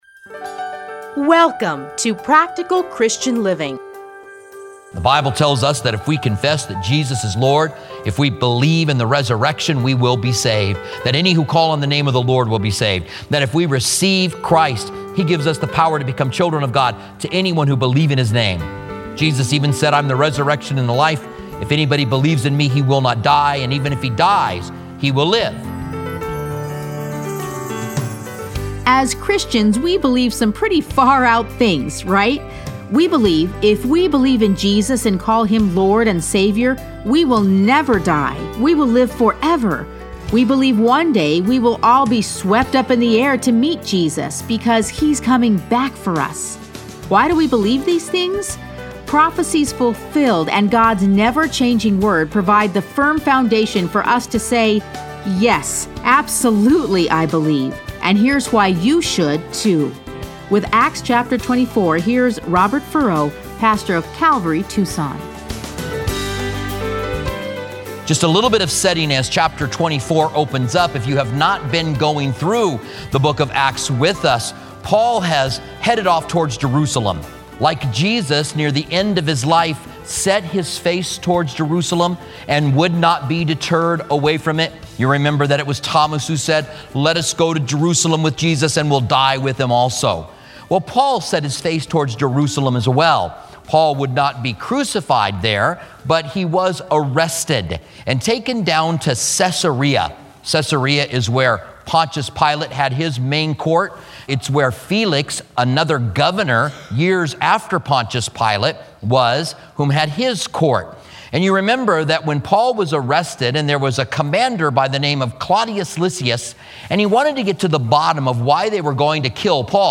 Listen to a teaching from Acts 24.